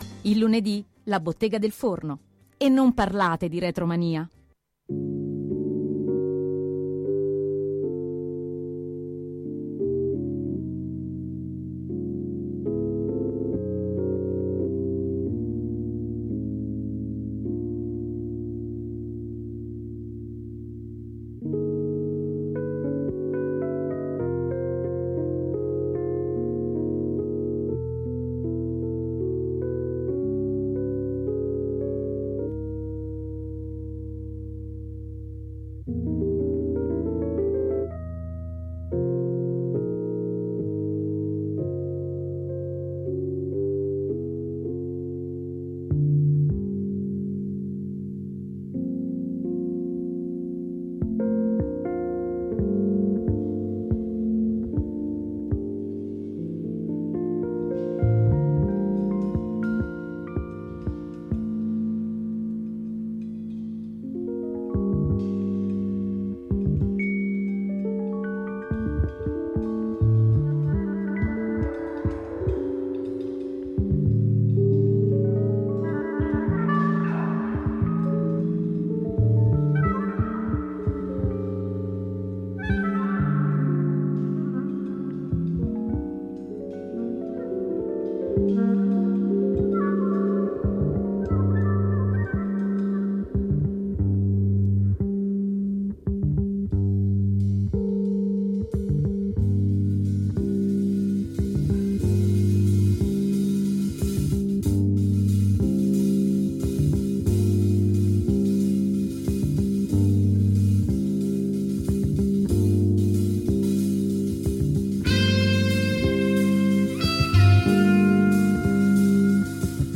Radio CIttà Aperta intervista il sassofonista Claudio Fasoli a cinquant’anni dal debutto de Il Perigeo | Radio Città Aperta
L’arrivo a Roma, gli incontri con Lucio Dalla al bar della casa discografica RCA , i festival italiani e internazionali, il rapporto con i Weather Report , questi alcuni dei temi affrontati nel corso della chiacchierata, preceduta e seguita dall’ascolto di due brani da quell’importante esordio.